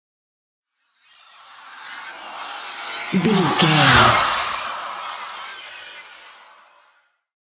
Airy pass by horror squeal
Sound Effects
In-crescendo
Atonal
scary
ominous
eerie
the trailer effect